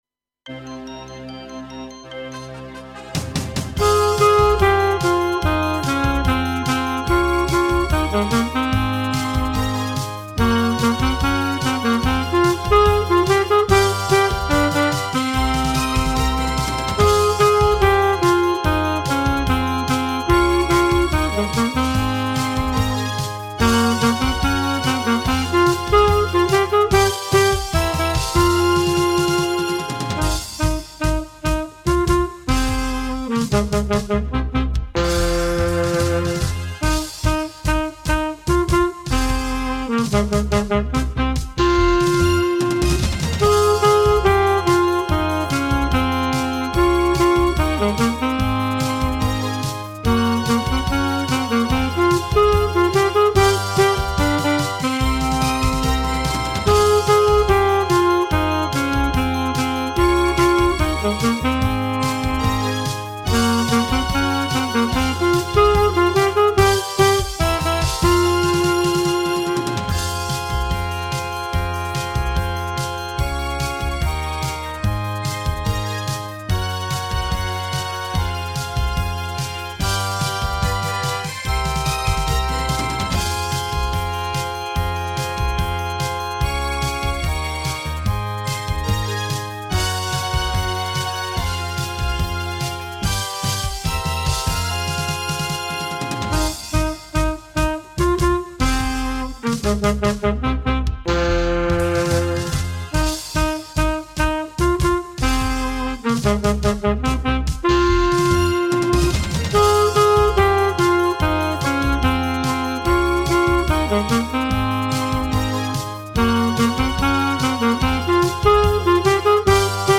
Avec saxophone alto moyen 148